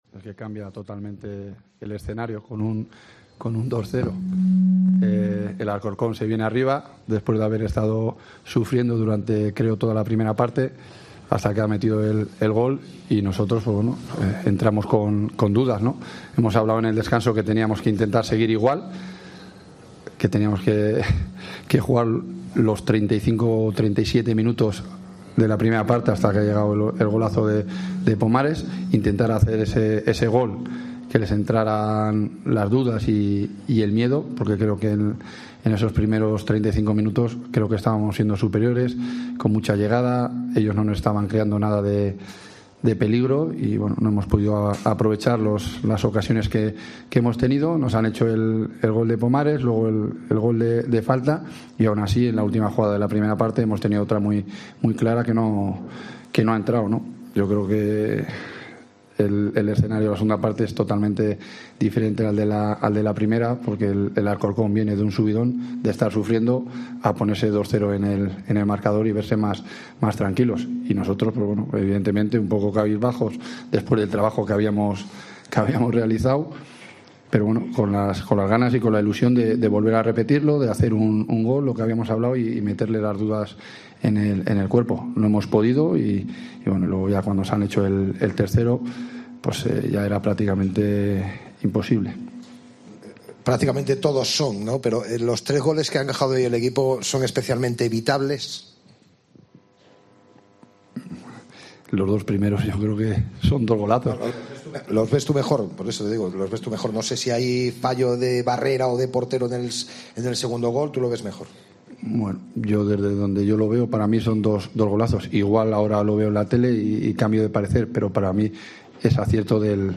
Escucha aquí el postpartido con declaraciones